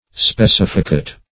specificate - definition of specificate - synonyms, pronunciation, spelling from Free Dictionary
Search Result for " specificate" : The Collaborative International Dictionary of English v.0.48: Specificate \Spe*cif"i*cate\ (sp[-e]*s[i^]f"[i^]*k[=a]t), v. t. [See Specify .] To show, mark, or designate the species, or the distinguishing particulars of; to specify.